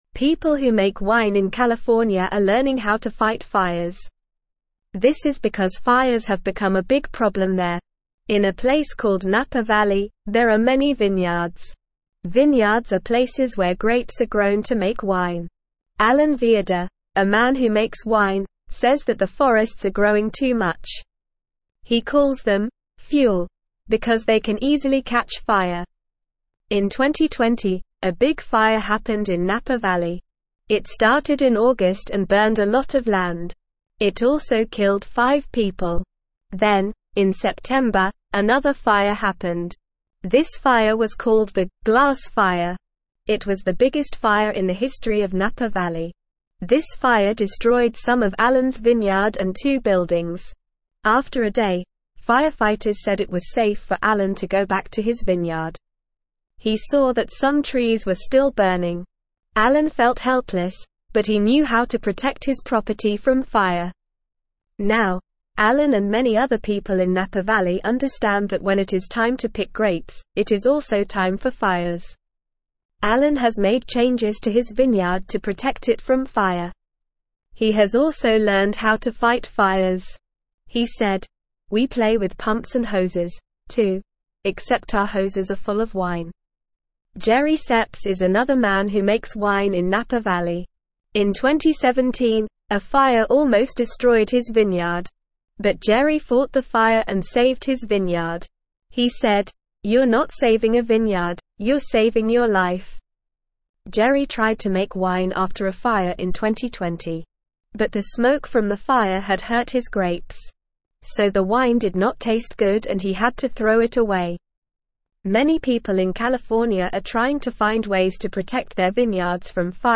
Normal
English-Newsroom-Beginner-NORMAL-Reading-California-Winemakers-Learn-to-Fight-Fires.mp3